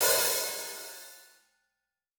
TC Live HiHat 07.wav